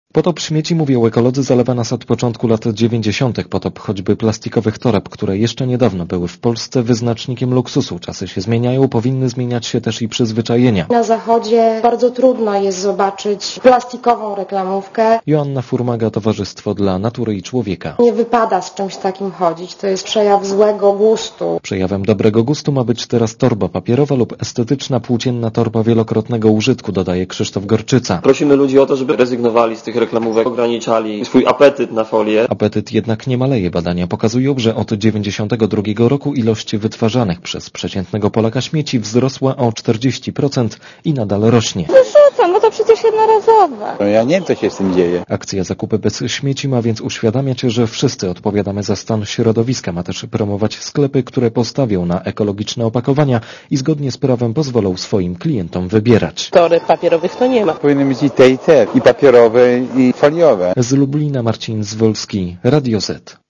Relacja reportera Radia Zet (250Kb)